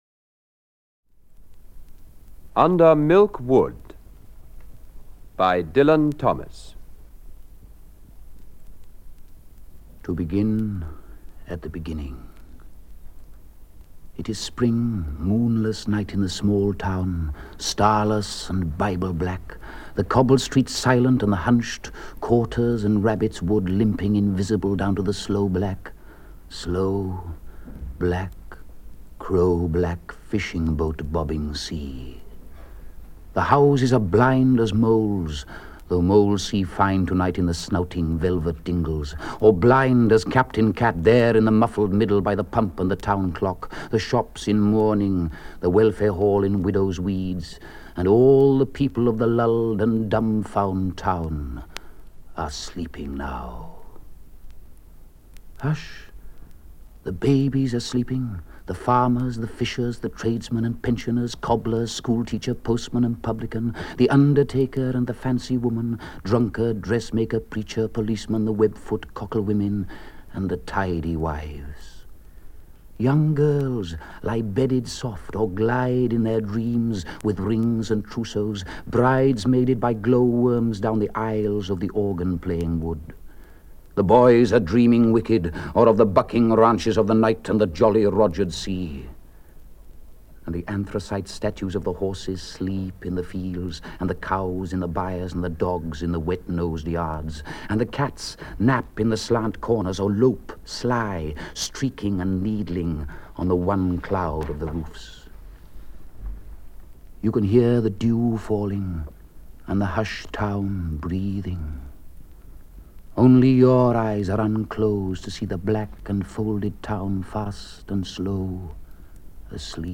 The Essential Dylan Thomas (EN) audiokniha
Ukázka z knihy
Thomas was a charismatic if idiosyncratic performer of his own poetry and stories and here is a representative selection.